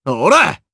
Kibera-Vox_Attack3_jp.wav